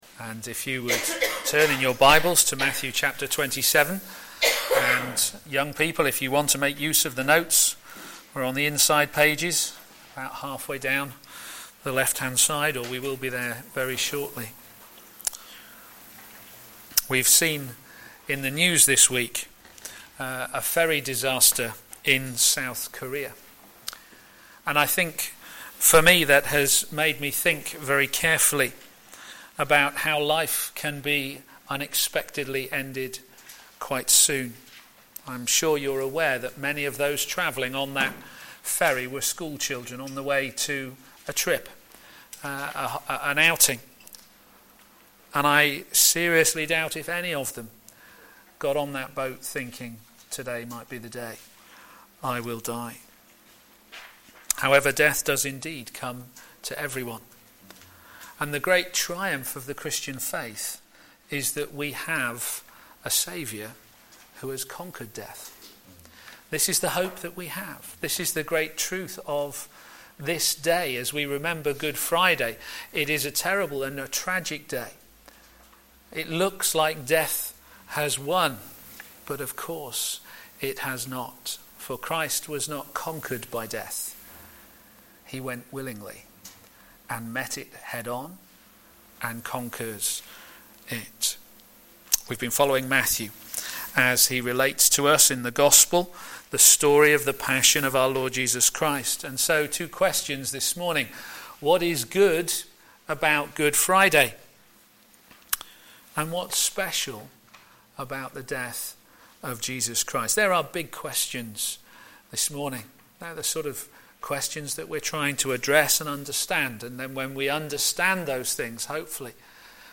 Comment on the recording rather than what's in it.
Media Library Media for a.m. Service on Fri 18th Apr 2014 10:30 Speaker